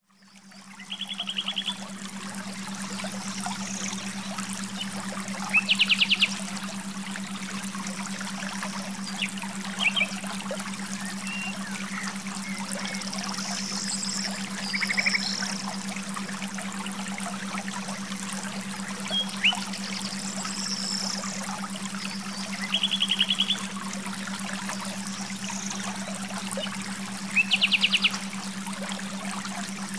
Beautiful nature scenes for relaxing.